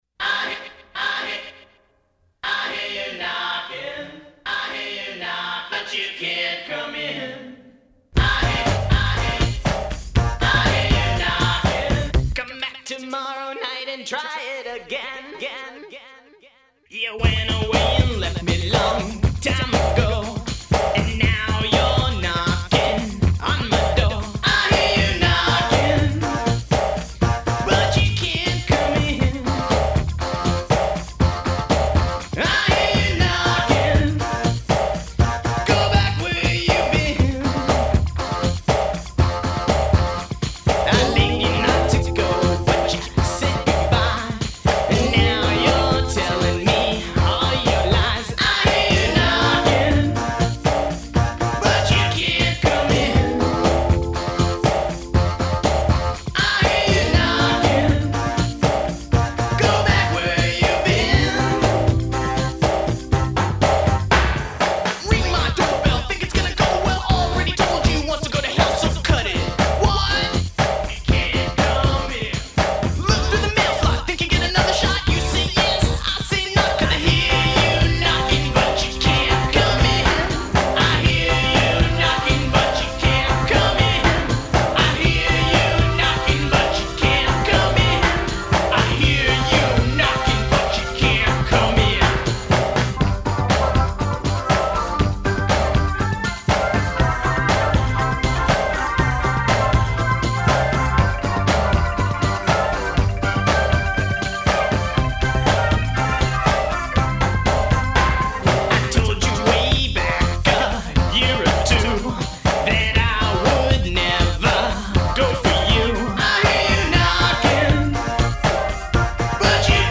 vocals and keyboards